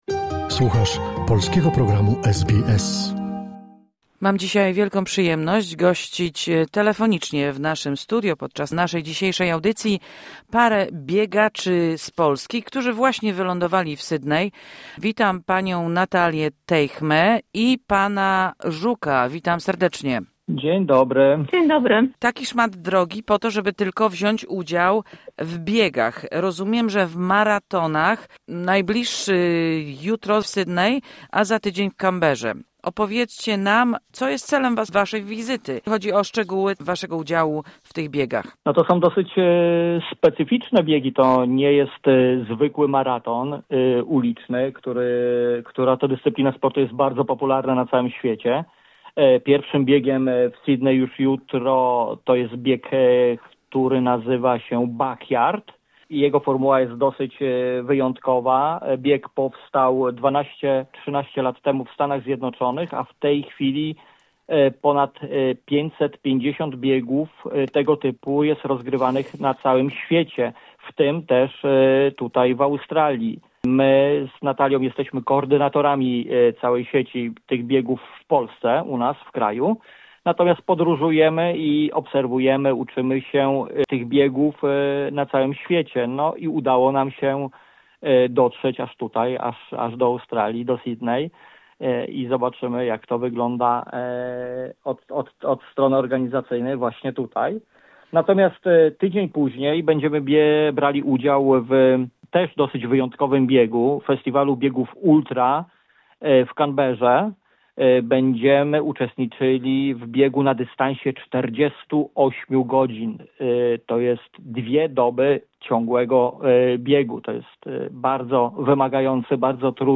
Rozmowa z polskimi maratończykami, którzy pobiegną w maratonach w Sydney i w Canberze.